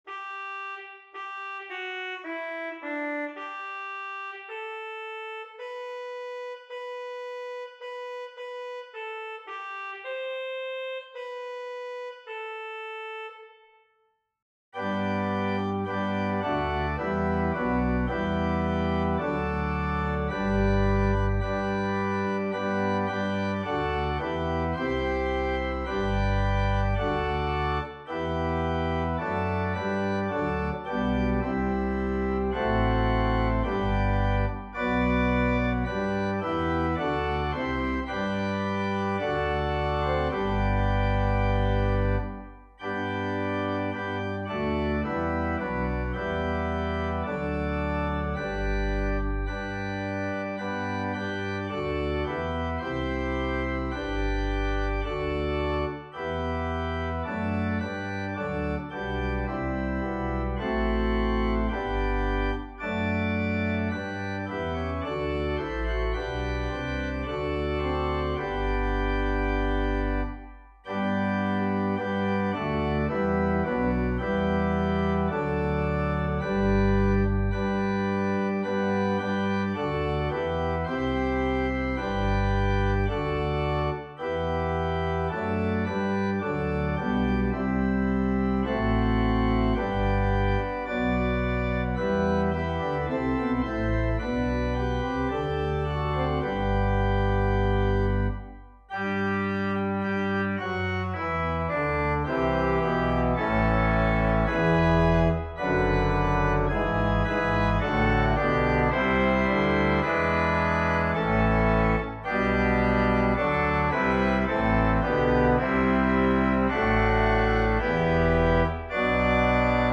Organ: Little Waldingfield